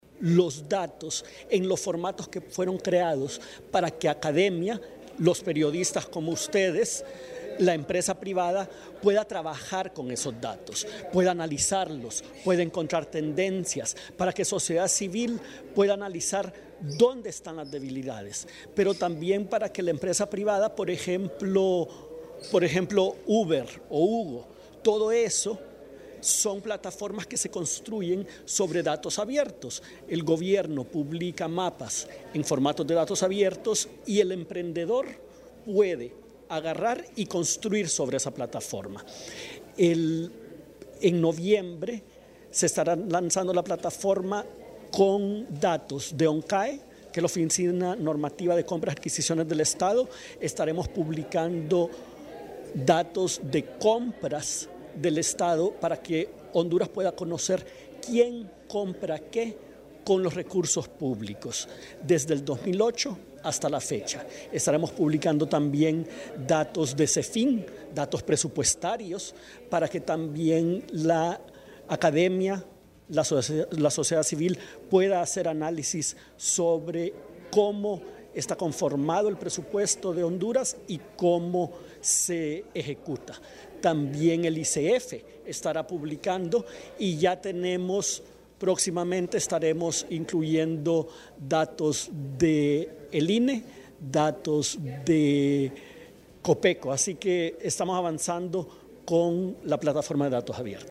A continuación parte de su última entrevista.